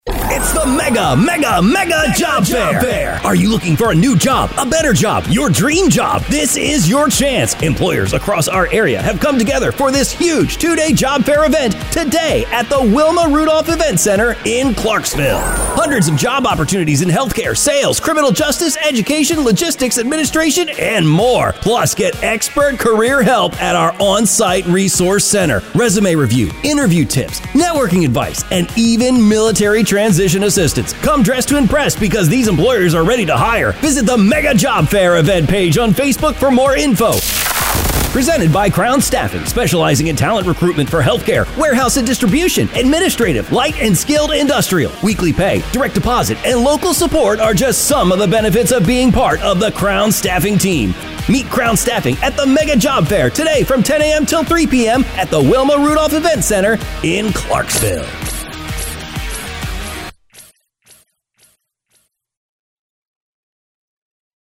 MEGA Job Fair Commerical Audio